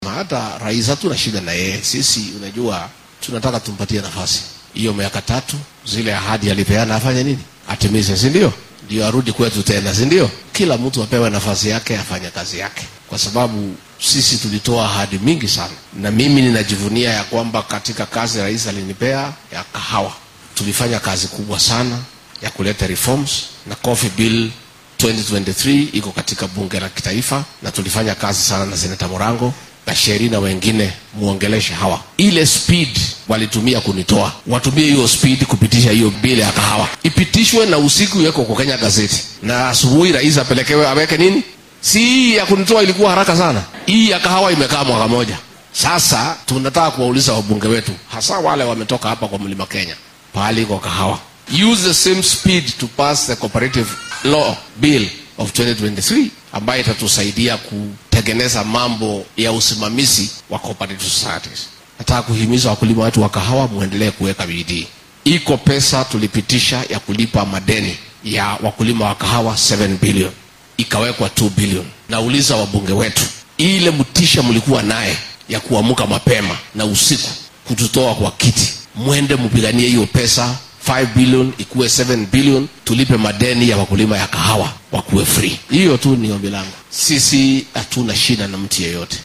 Xilli uu maanta ka qayb galay aas ka dhacay deegaanka Kabare ee ismaamulka Kirinyaga ayuu Gachagua xusay inuusan wax dhib ah ku qabin madaxweynaha dalka.